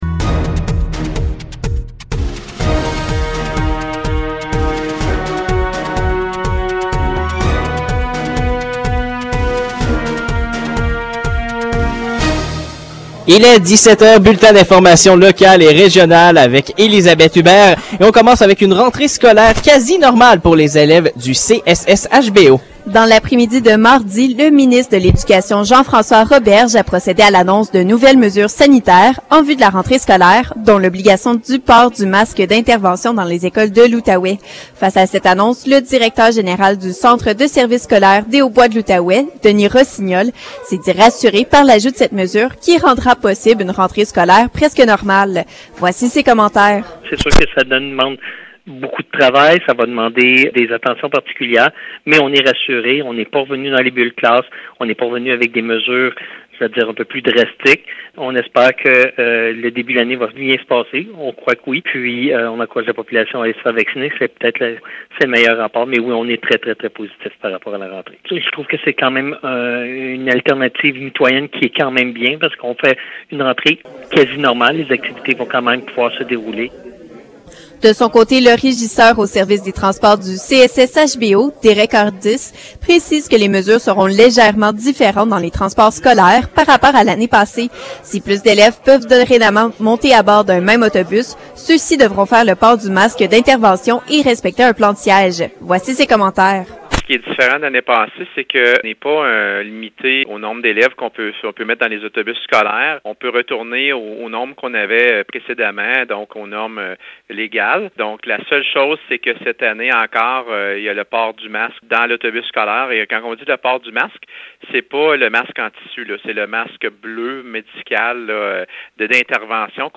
Nouvelles locales - 25 août 2021 - 17 h